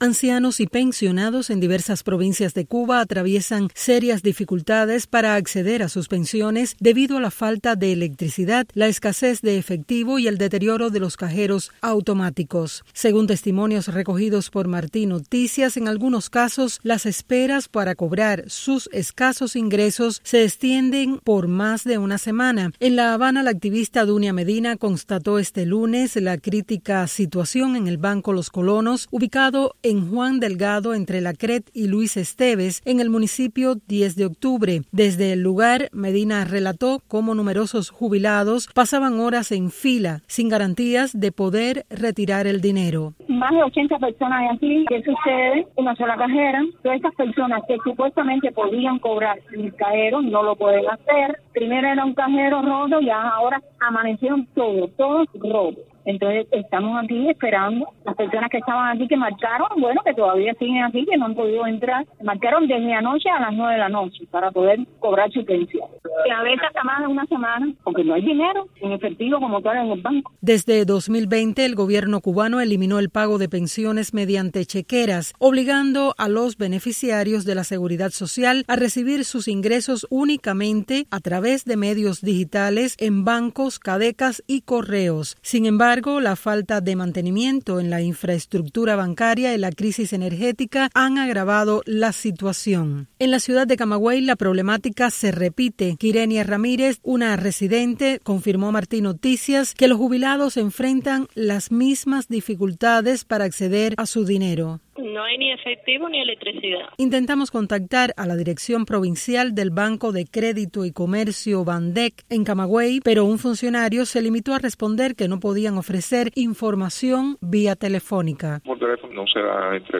Las principales informaciones relacionadas con Cuba, América Latina, Estados Unidos y el resto del mundo de los noticieros de Radio Martí en la voz de nuestros reporteros y corresponsales